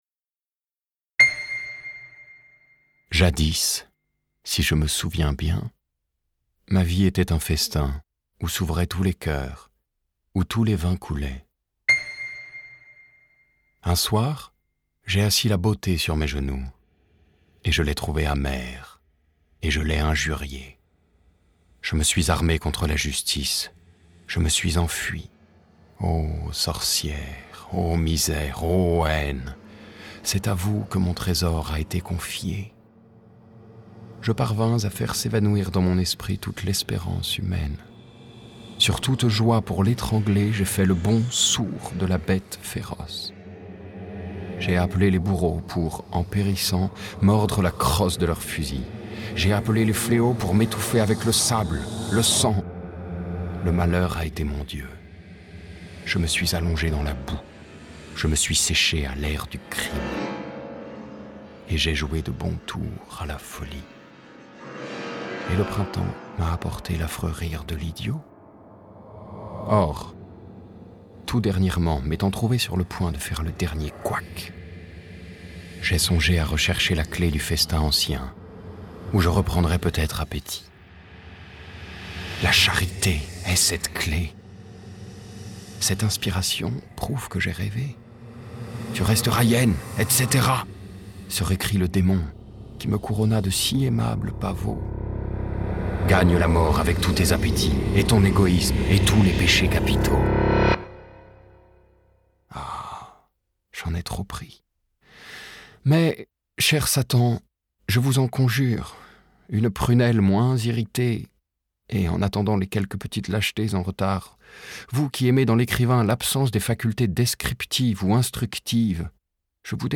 Voix Off, voix masculine, homme, voix grave, voix très grave, voix chaude, voix paternelle, voix sécurisante, voix douce, voix puissante.
Sprechprobe: Sonstiges (Muttersprache):